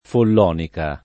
[ foll 0 nika ]